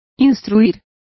Complete with pronunciation of the translation of enlighten.